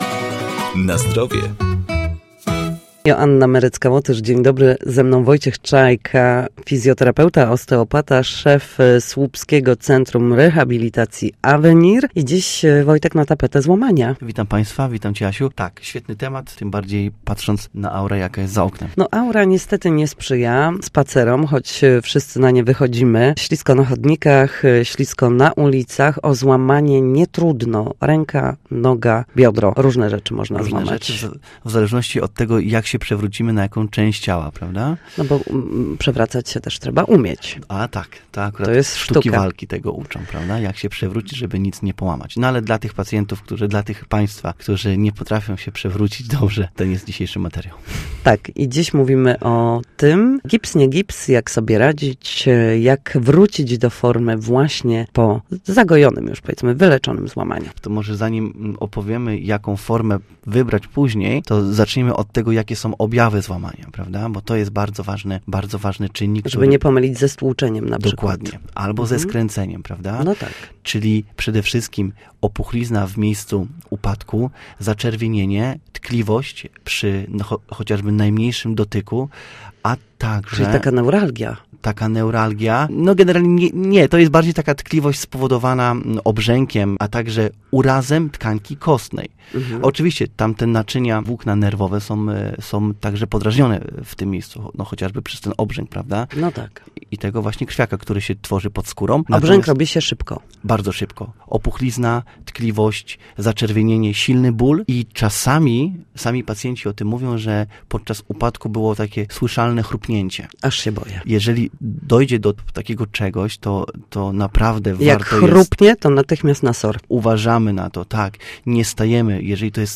Nasi goście – lekarze, fizjoterapeuci – w audycji „Na Zdrowie” odpowiadają na pytania dotyczące najczęstszych dolegliwości.